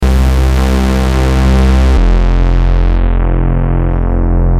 Free MP3 vintage Sequential circuits Pro-1 loops & sound effects 1